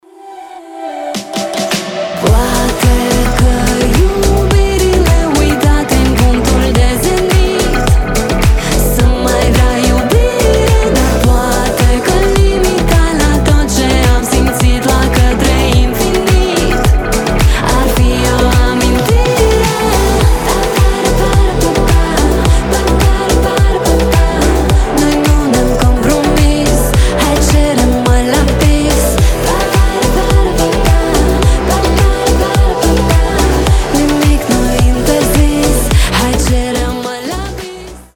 красивые
женский голос
румынские